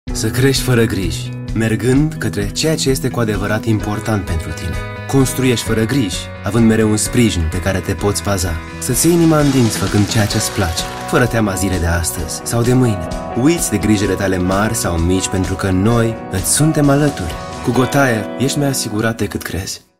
dynamisch, frisch, mittelkräftig, seriös
Sprechprobe: Industrie (Muttersprache):